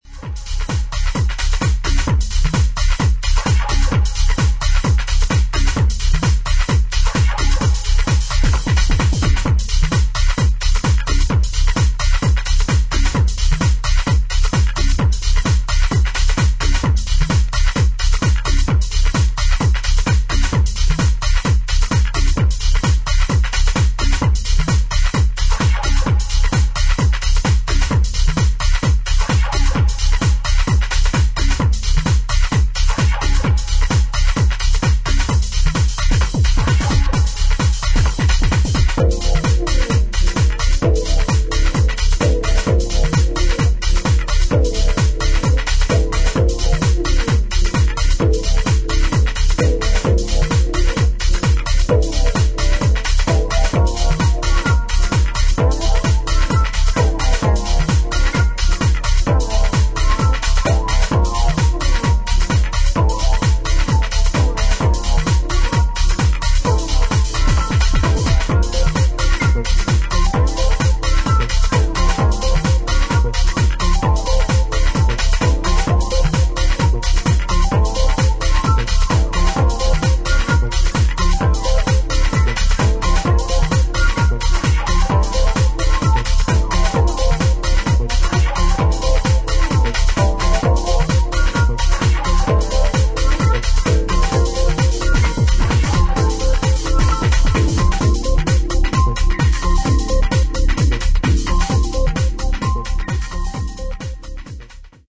ダイナミックで粗削りな音作りが魅力の、90's UKアンダーグラウンドハウス隠れた傑作。